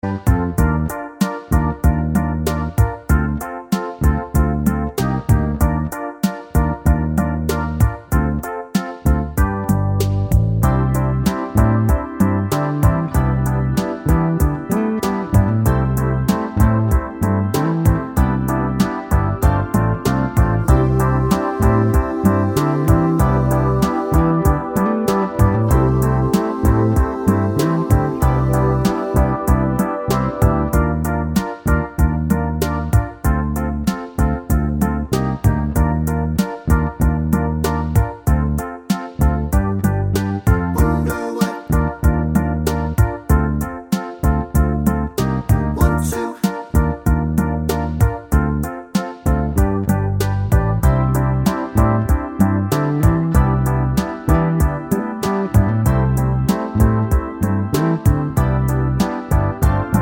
Minus Sax Solo Pop (1980s) 4:04 Buy £1.50